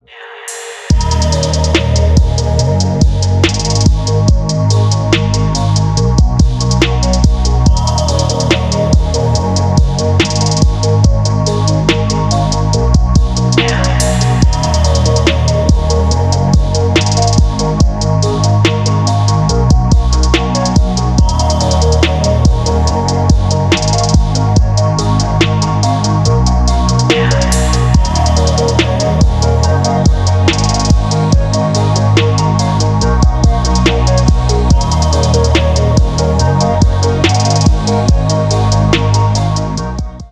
Stereo
Электроника зарубежные клубные спокойные